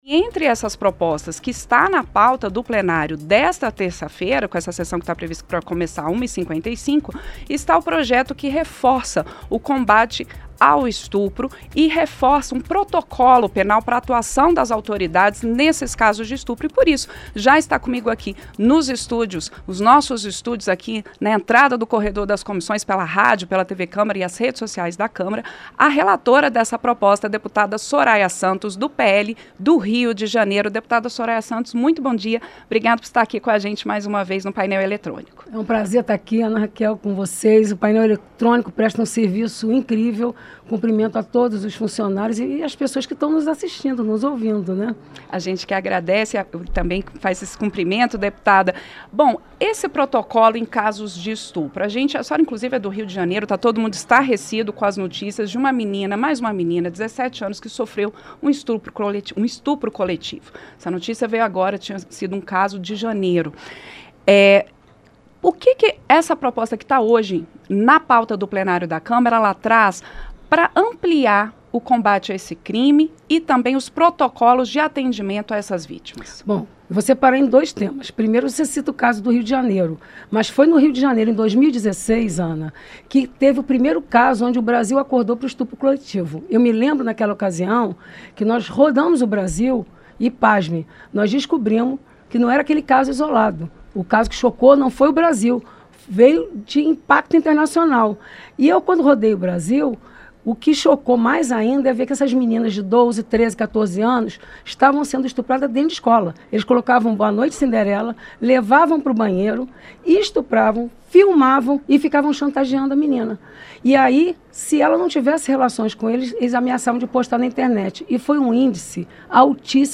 Entrevista - Dep. Soraya Santos (PL-RJ)